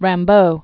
(răm-bō, răɴ-), Jean Nicolas Arthur 1854-1891.